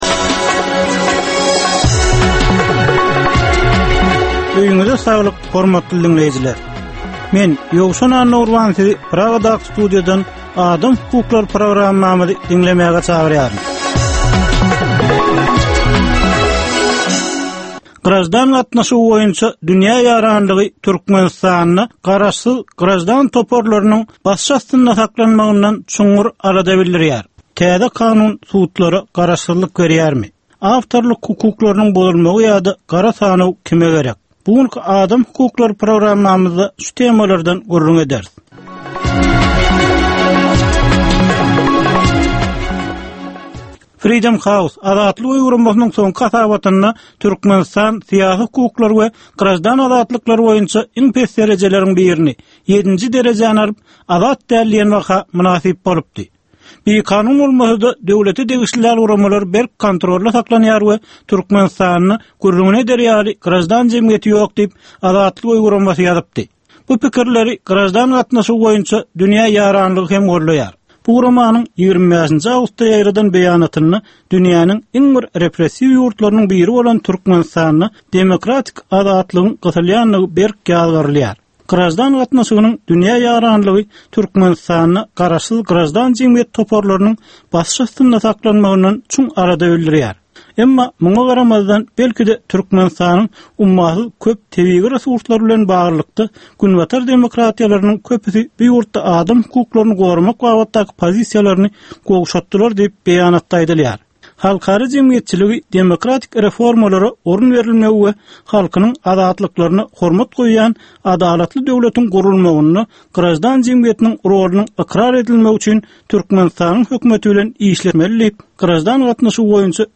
Türkmenistandaky ynsan hukuklarynyň meseleleri barada 15 minutlyk ýörite programma. Bu programmada ynsan hukuklary bilen baglanyşykly anyk meselelere, problemalara, hadysalara we wakalara syn berilýar, söhbetdeşlikler we diskussiýalar gurnalýar.